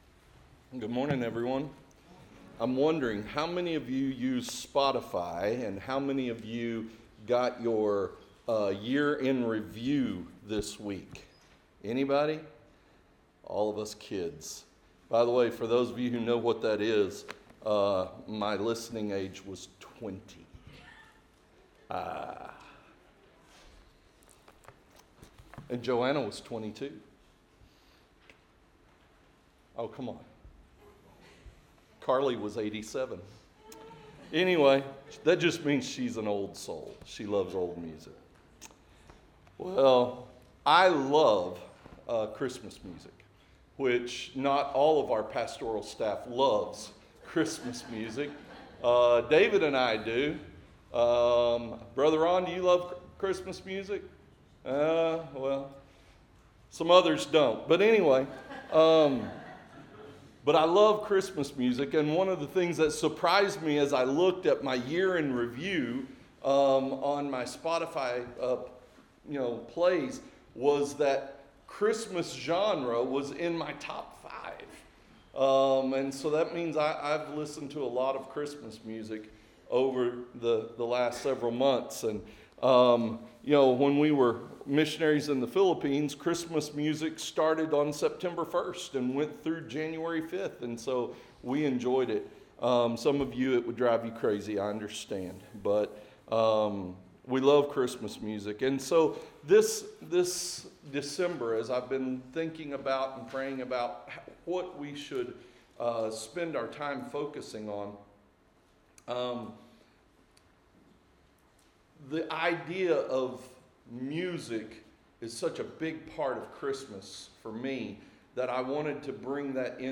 YouVersion Interactive Sermon Notes Series: The Song of Emmanuel Tagged with christmas